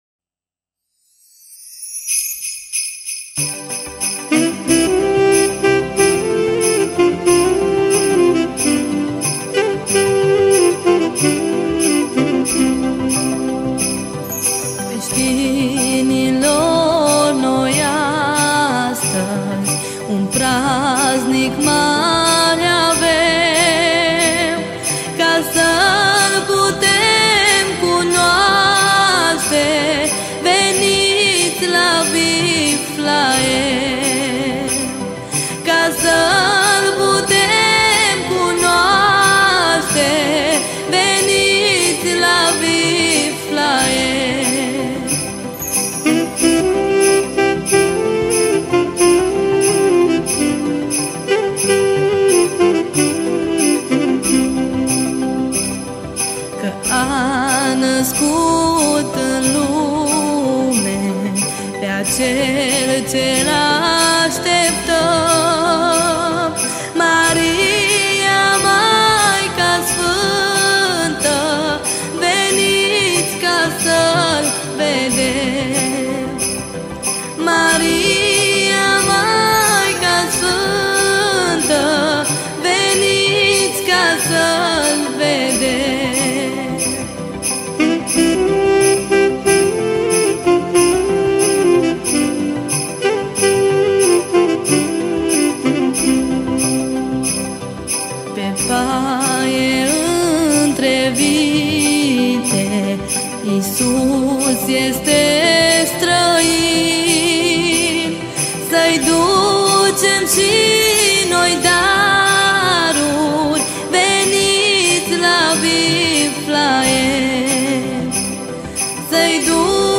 Data: 12.10.2024  Colinde Craciun Hits: 0